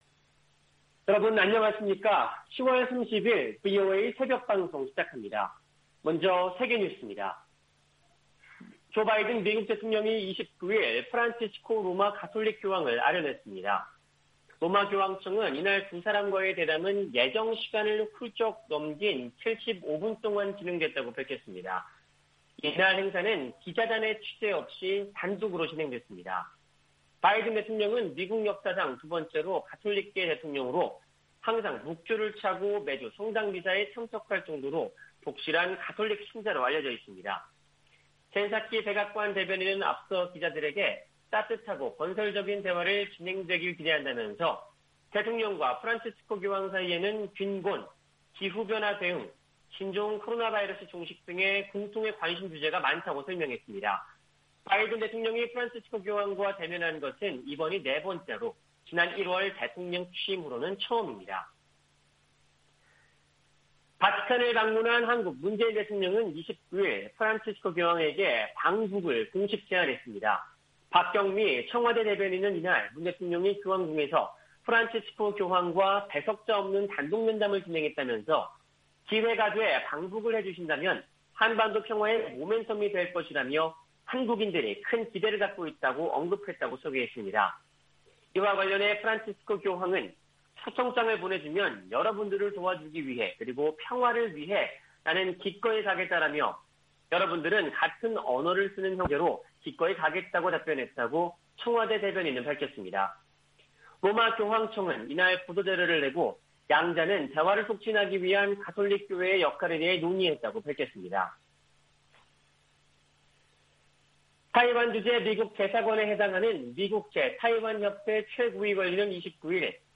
세계 뉴스와 함께 미국의 모든 것을 소개하는 '생방송 여기는 워싱턴입니다', 2021년 10월 30일 아침 방송입니다. '지구촌 오늘'에서는 기후 변화 등을 의제로 주요 20개국(G20) 정상회의가 개막하는 소식, '아메리카 나우'에서는 바이든 대통령이 복지 지출 규모를 절반으로 줄여 제안한 이야기 전해드립니다.